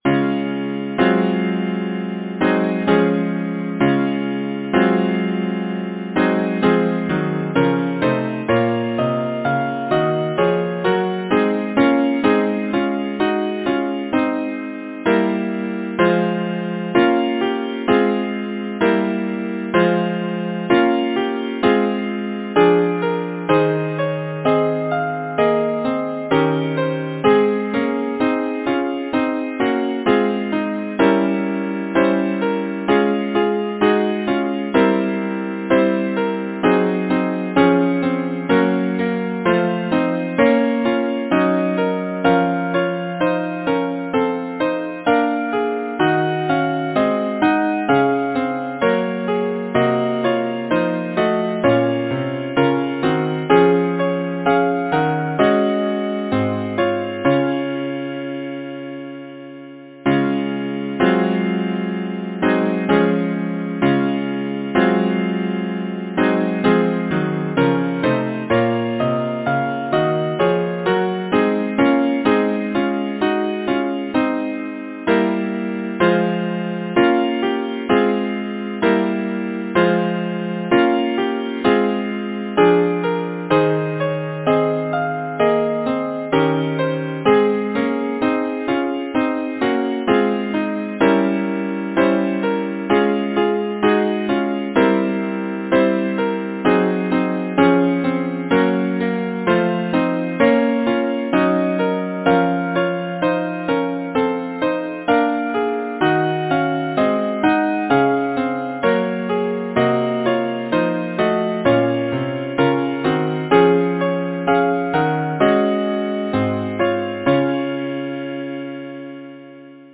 Title: Of a’ the airts Composer: Charles Jessop Lyricist: Robert Burns Number of voices: 4vv Voicing: SATB Genre: Secular, Partsong
Language: English Instruments: A cappella